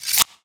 AWP_Dagger_Sheath.wav